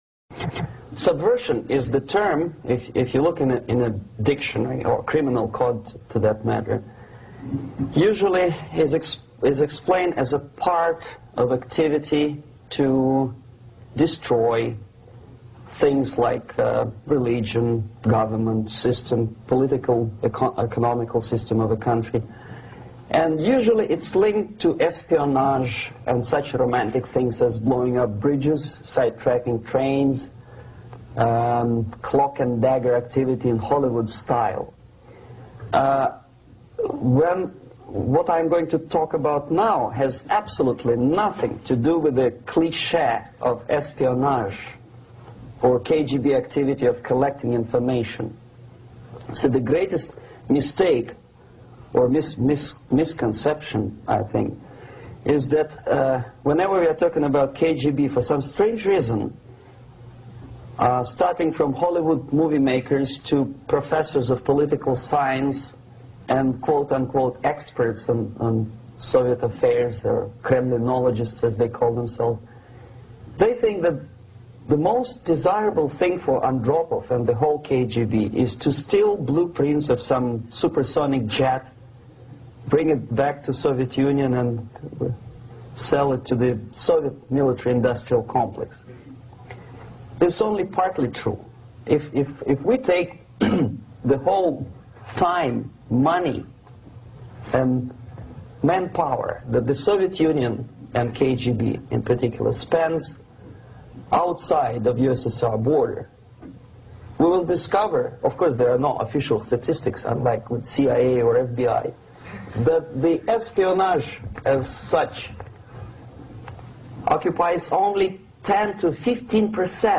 Yuri Bezmenov Psychological Warfare Subversion & Control of Western Society (Full Lecture)